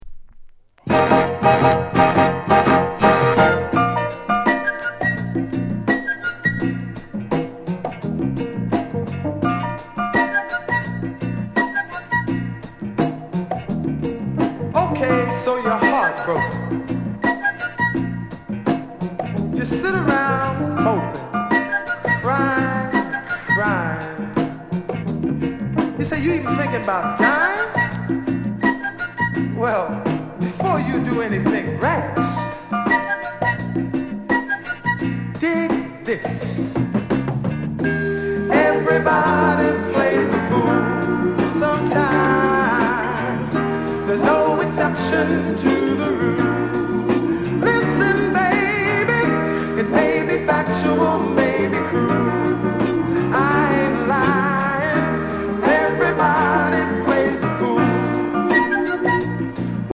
JAMAICA SOUL